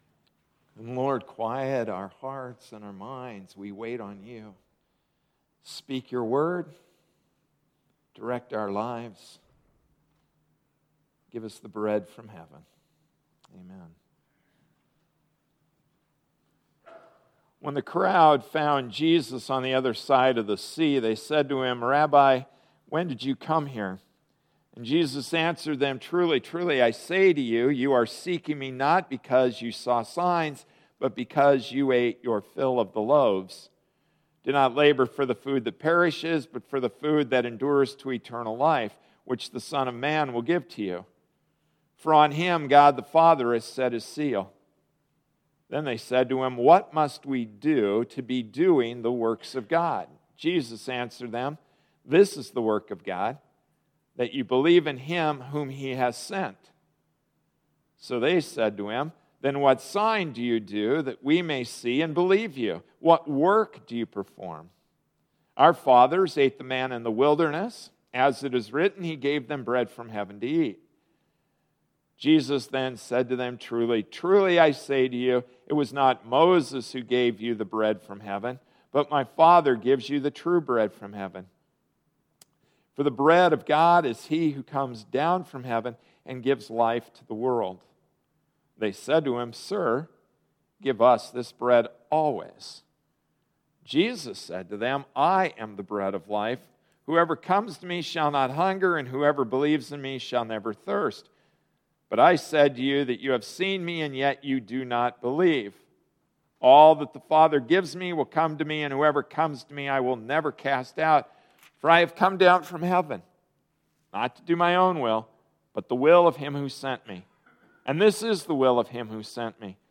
March 9, 2014 Expiration Date Passage: John 6:25-40 Service Type: Sunday Morning Service “Expiration Date,” John 6:25-40 Introduction: The rise in popularity of Whole Foods and organic products despite the higher price.